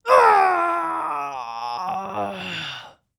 Male_Death_Shout_05.wav